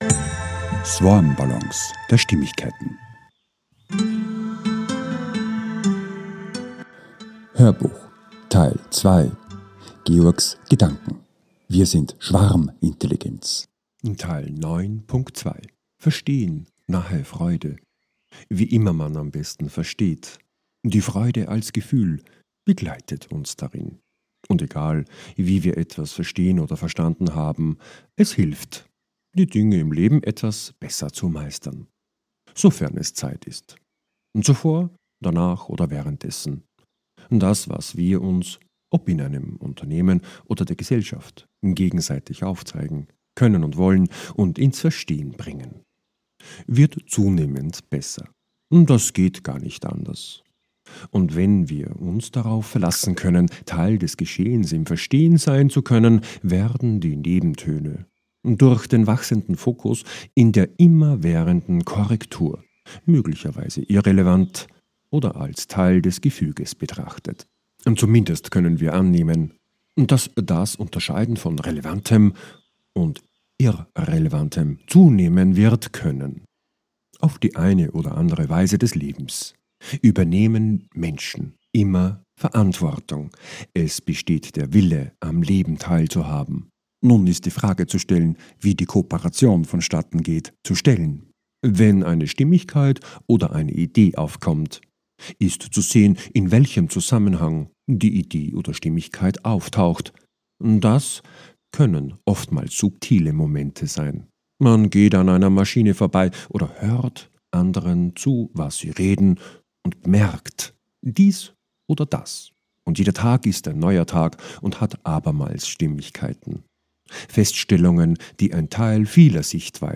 HÖRBUCH TEIL 2 - 09.3 - WIR SIND SCHWARMINTELLIGENZ 2 - VERSTEHEN nahe FREUDE ~ SwarmBallons A-Z der Stimmigkeit Podcast